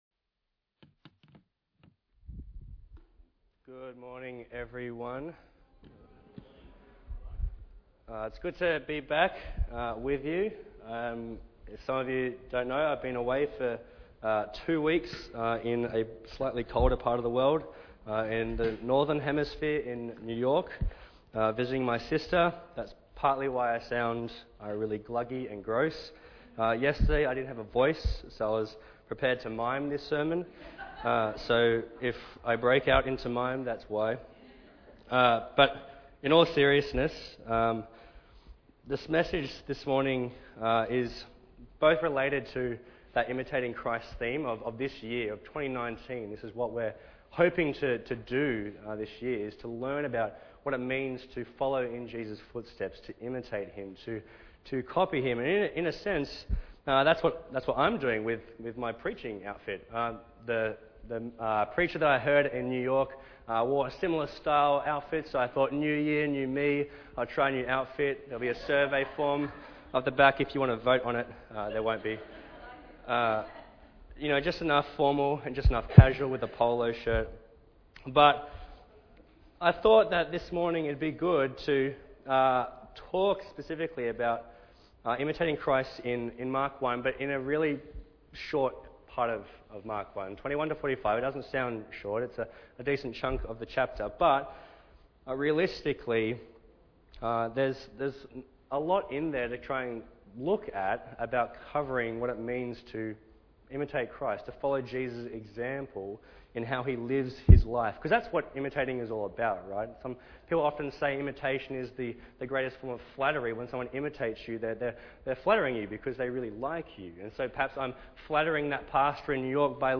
Bible Text: Mark 1 | Preacher